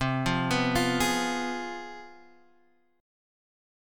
Fdim/C chord